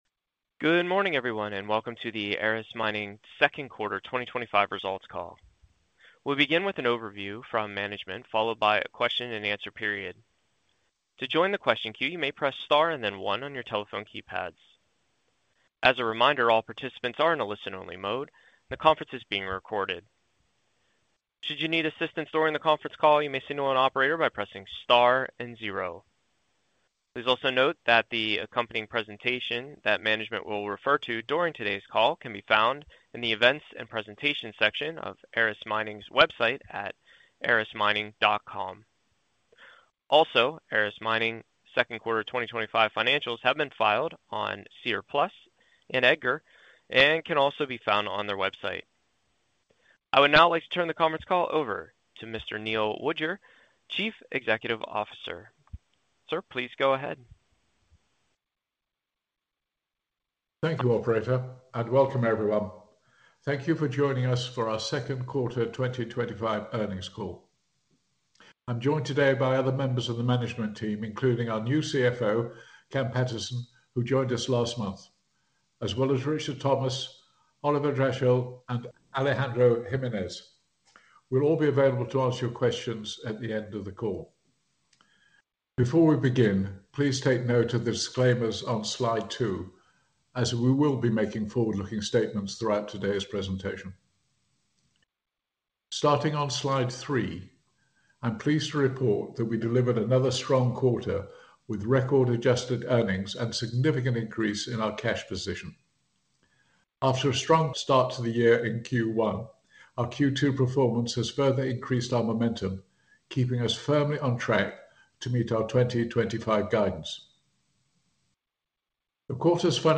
Q2-2025-Earnings-Conference-Call-Recording.mp3